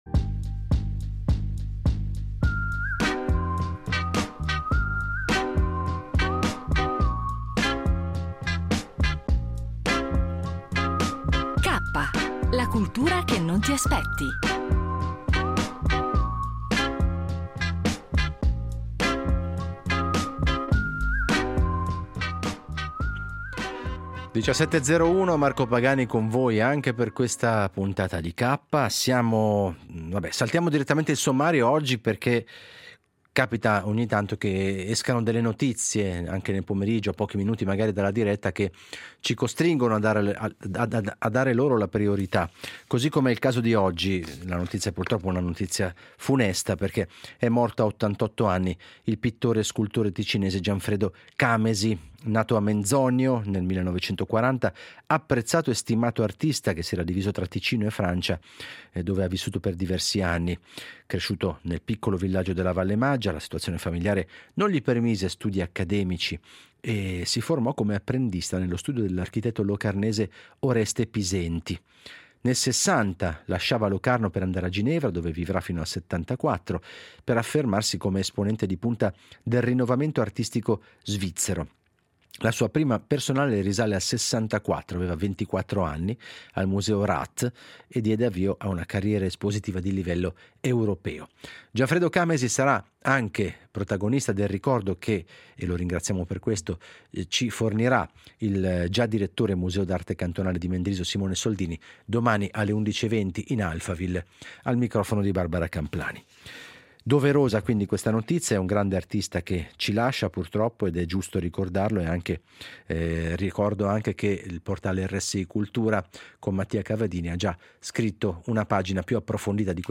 Si passa poi al cinema , con un’intervista al regista Giuseppe Piccioni , che racconta il suo nuovo film Giovanni , dedicato alla vita e alla sensibilità poetica di Giovanni Pascoli .